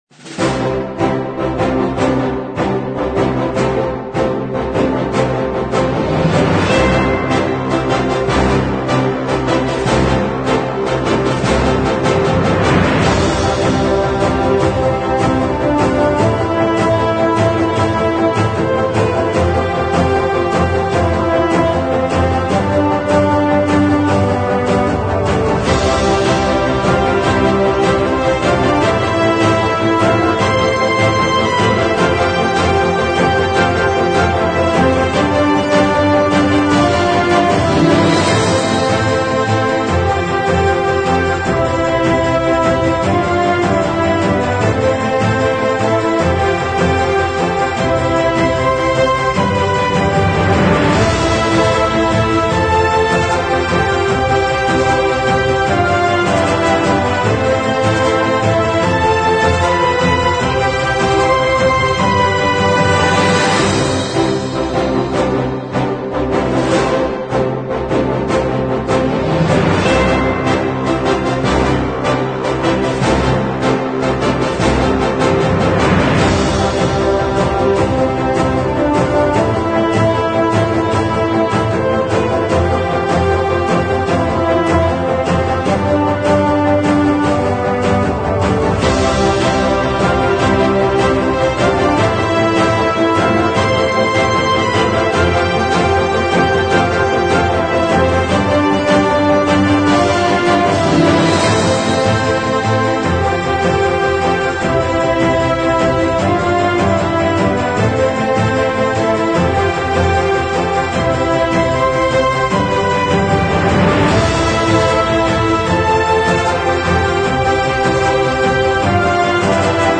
描述：是一个充满力量的组成，带来了所有史诗般的气氛和力量。